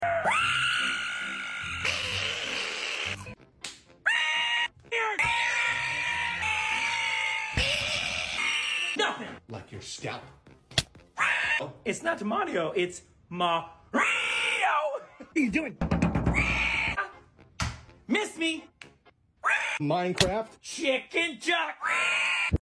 Bruh sound effects free download